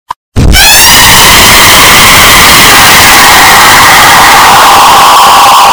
Chicken Loud Asf Téléchargement d'Effet Sonore
Chicken Loud Asf Bouton sonore